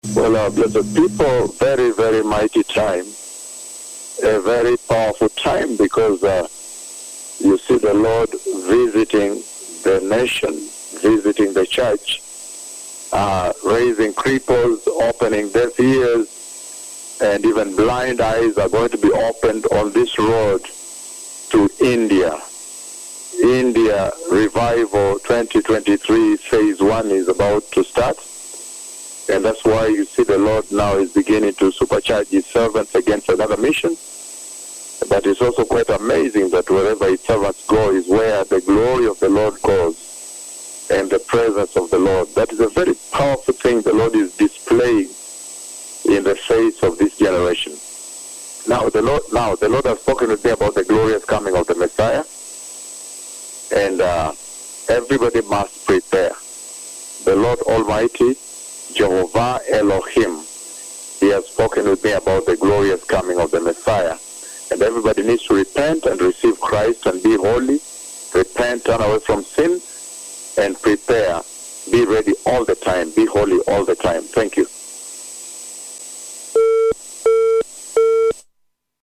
3. PROPHÉTIES ET ENSEIGNEMENTS
2023-10-27 LES MÉGA PUISSANTS PROPHÈTES DE YAHWEH EN DIRECT - LE SEIGNEUR INJECTE EN PUISSANCE SES SERVITEURS QUI SE DIRIGENT VERS L'INDE, LE MESSIE REVIENT, QUE TOUS SE REPENTE, SE PRÉPARE ET SOYEZ PRÊT A TOUS MOMENTS.